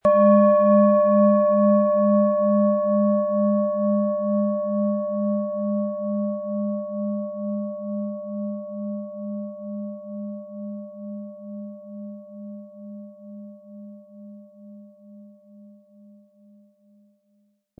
Planetenton
Die Planetenklangschale Uranus ist handgefertigt aus Bronze.
Um den Originalton der Schale anzuhören, gehen Sie bitte zu unserer Klangaufnahme unter dem Produktbild.
Der richtige Schlegel ist kostenlos dabei, der Klöppel lässt die Klangschale voll und angenehm anklingen.